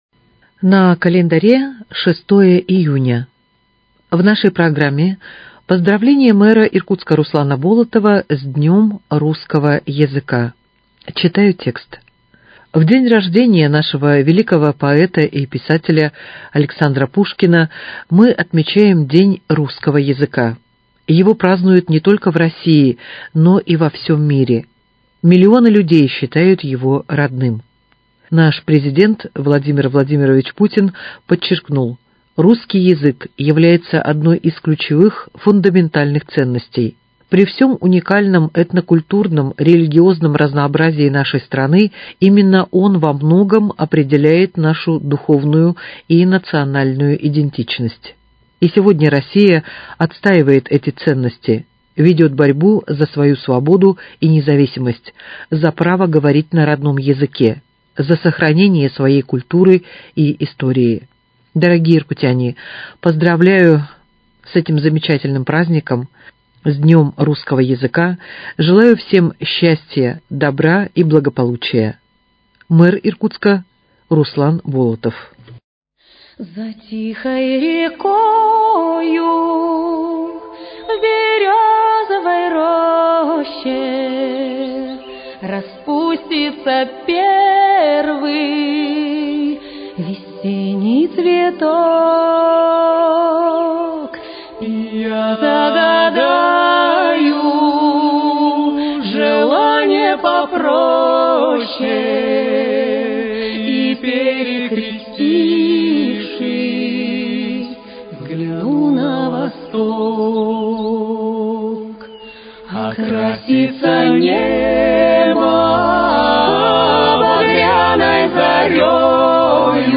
Поздравление мэра г.Иркутска Руслана Болотова с Днём русского языка.